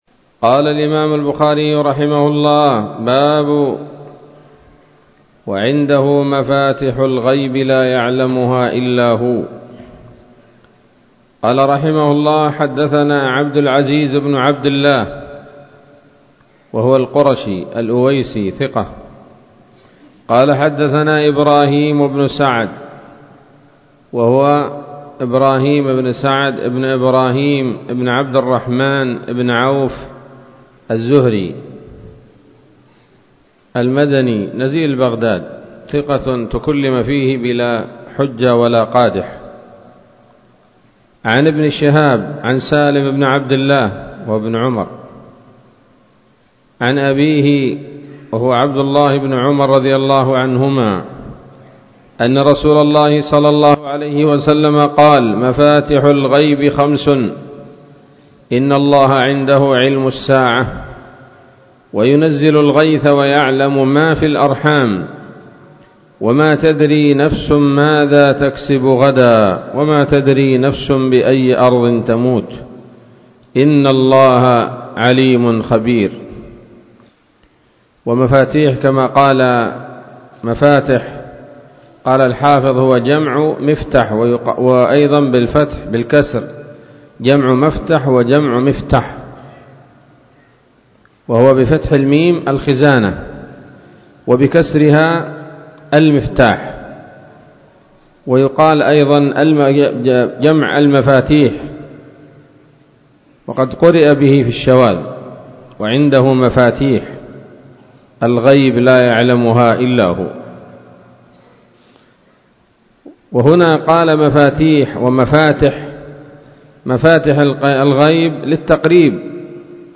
الدرس التاسع والتسعون من كتاب التفسير من صحيح الإمام البخاري